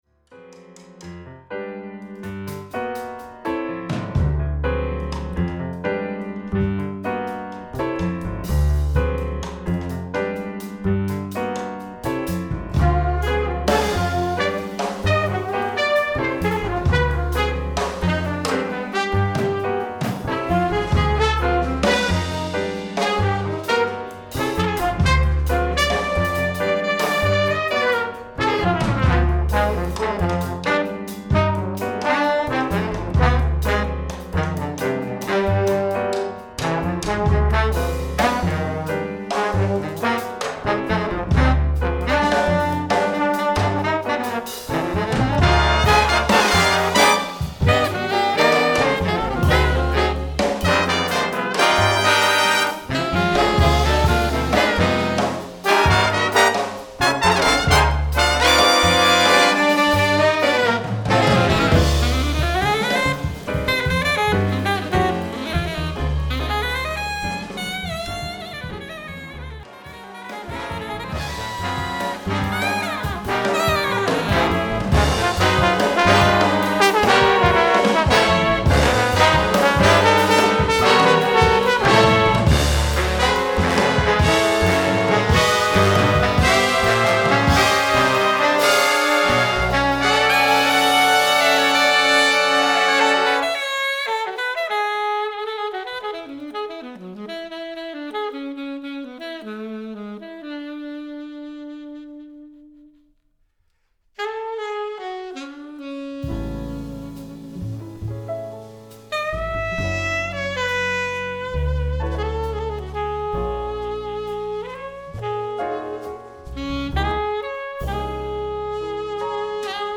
Grammy-Winning Saxophonist and Composer
Doubles: soprano sax, flute, clarinet
Solos: alto sax, trombone